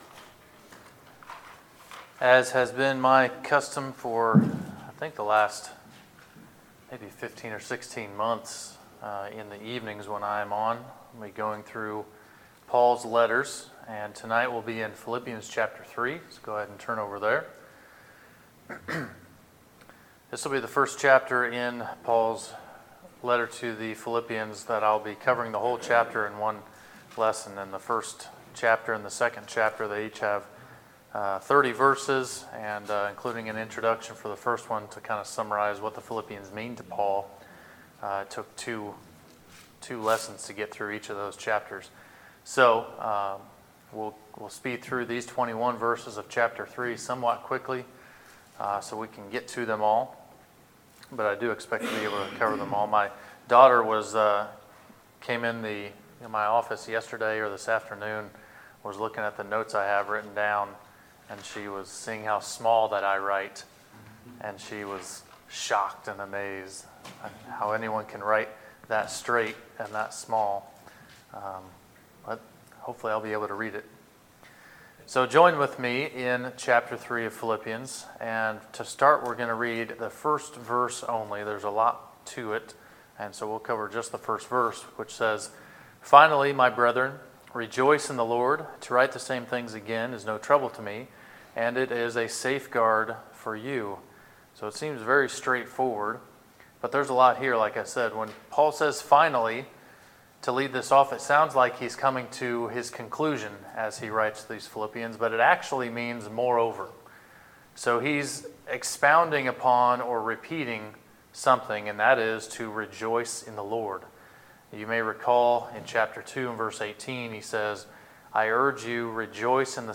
Sermons, January 6, 2019